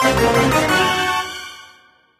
laser_panic_bad_01.ogg